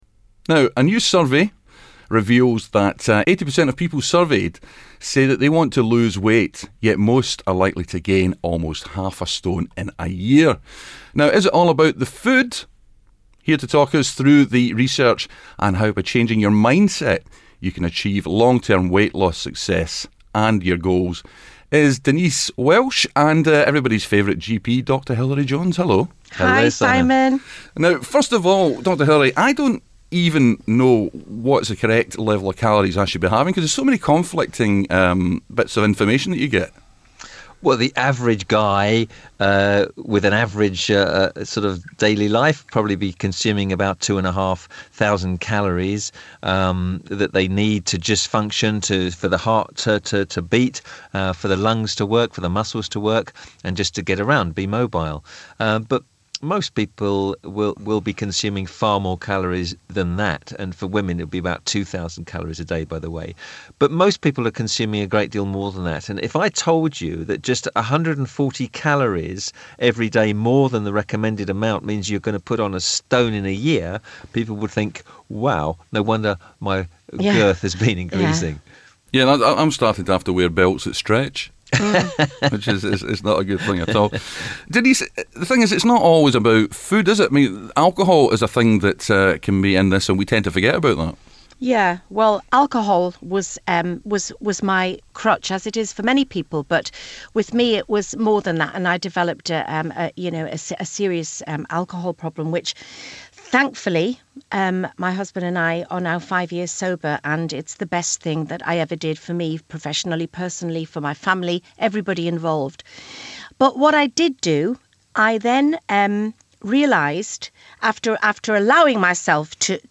Dr Hilary Jones and actress Denise Welch discuss weight loss and more specifically how you should be preparing your mind to eat healthily.